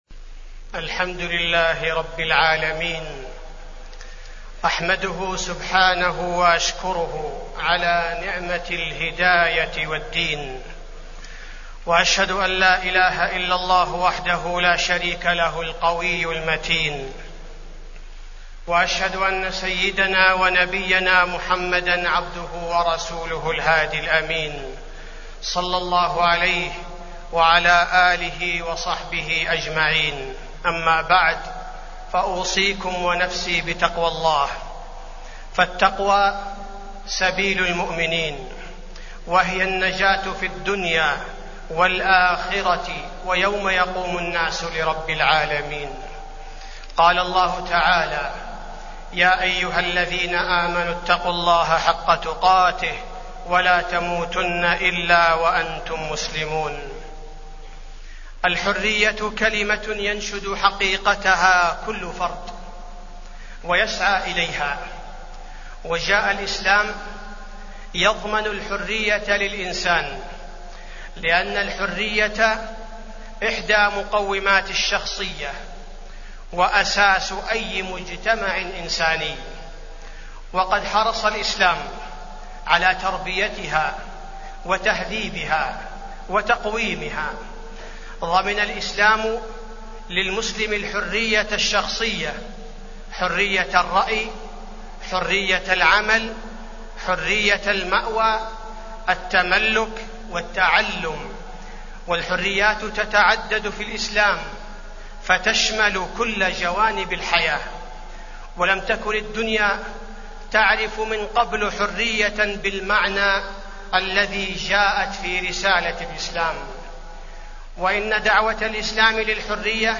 تاريخ النشر ٢٧ ربيع الأول ١٤٢٩ هـ المكان: المسجد النبوي الشيخ: فضيلة الشيخ عبدالباري الثبيتي فضيلة الشيخ عبدالباري الثبيتي الحرية في الإسلام